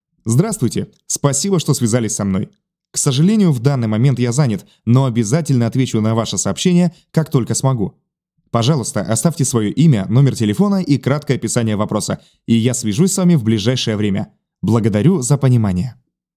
Автоответчик
Муж, Автоответчик/Молодой